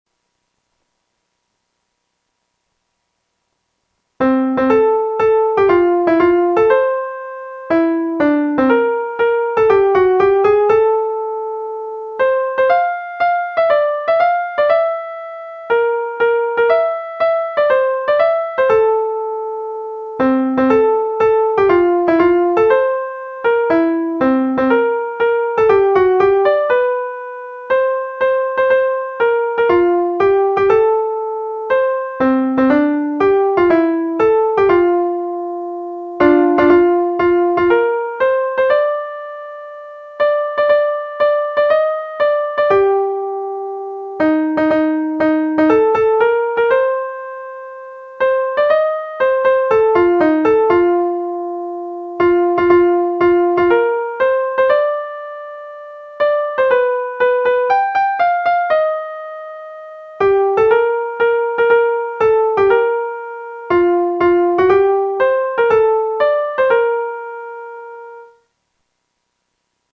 - execução em computador